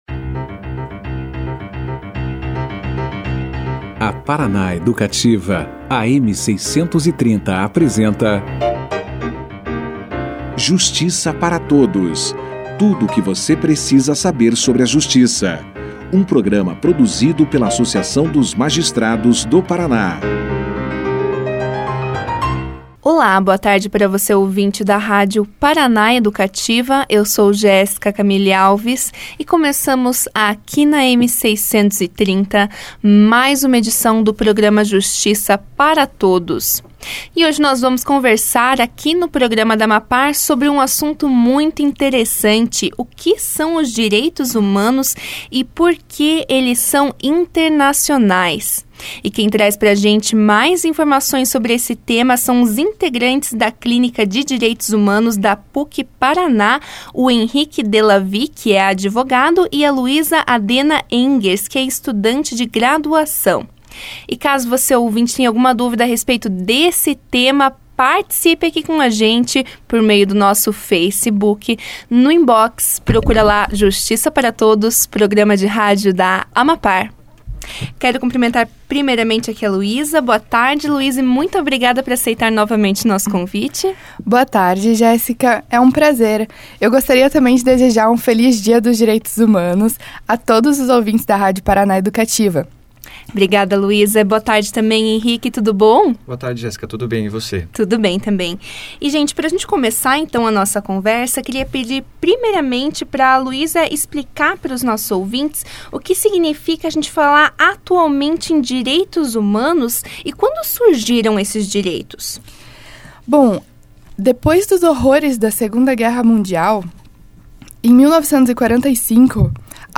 Quando surgiram os direitos humanos, as maiores conquistas desde a criação da Declaração e os ataques direcionados aos Direitos Humanos ao redor do mundo, foram questões esclarecidas no início da entrevista. Na oportunidade, além de apontarem os sistemas de proteção dos Direitos Humanos, os convidados esclareceram como a ONU lida com práticas culturais que violam estes direitos.